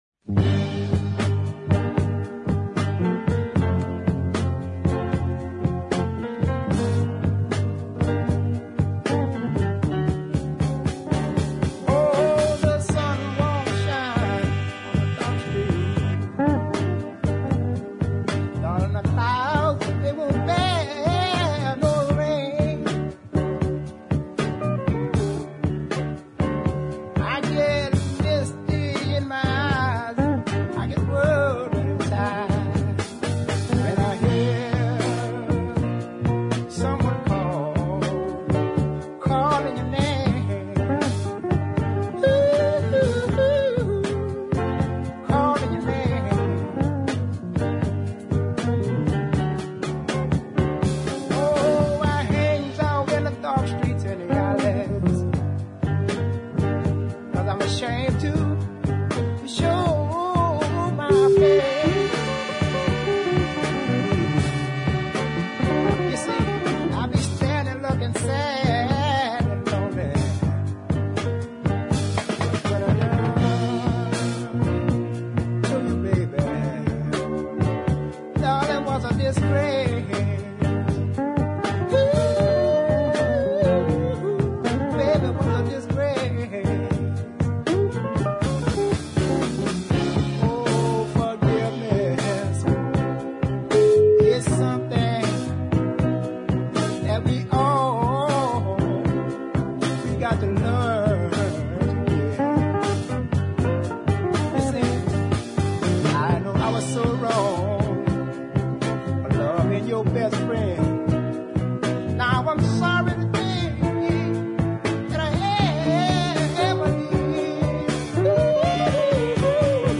There were two ballads amongst the cuts